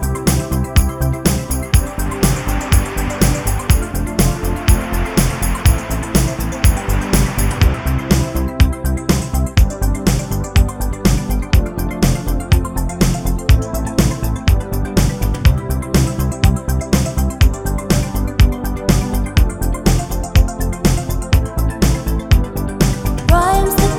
for solo male Pop (1980s) 3:30 Buy £1.50